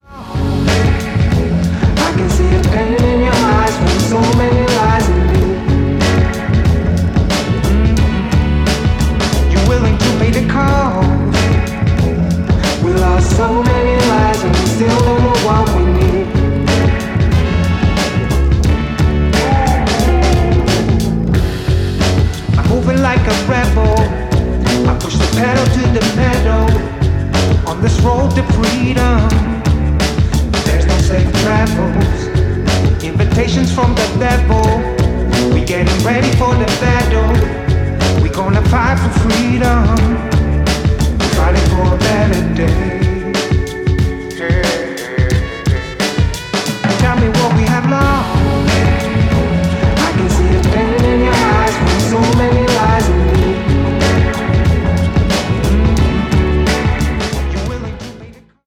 Hip Hop/Dj Tools
cuerdas envolventes